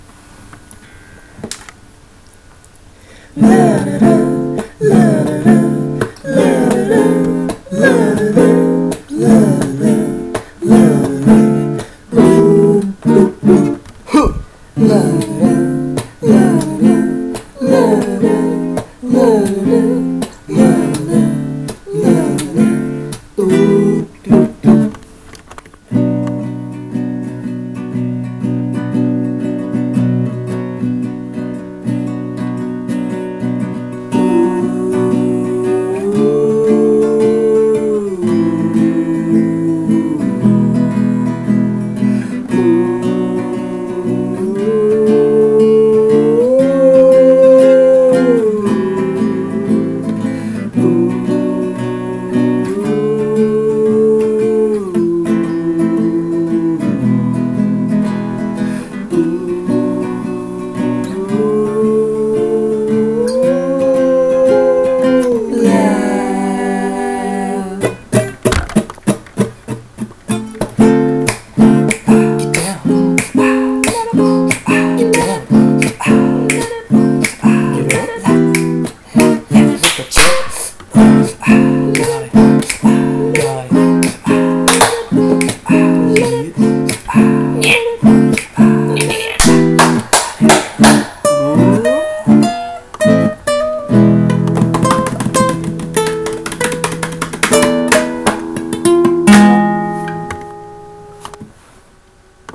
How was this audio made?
This one was made using the mic on my laptop.